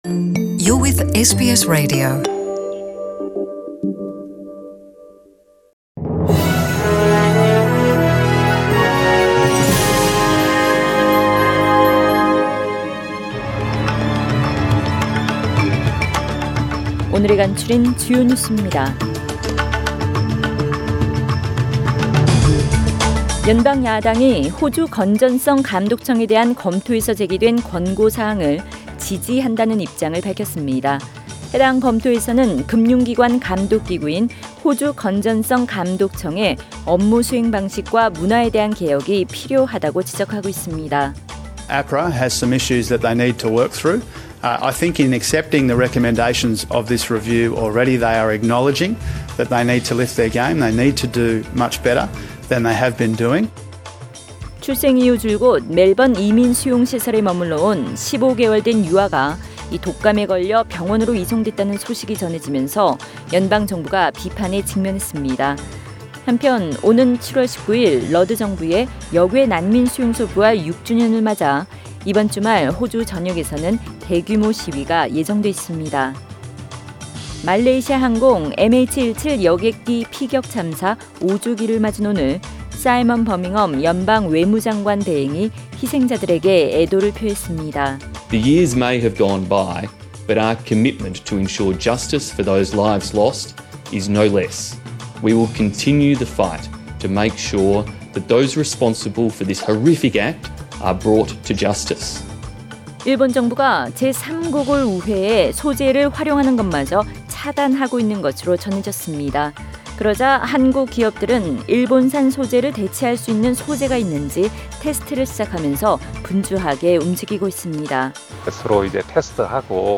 2019년 7월 17일 수요일 저녁의 SBS Radio 한국어 뉴스 간추린 주요 소식을 팟 캐스트를 통해 접하시기 바랍니다.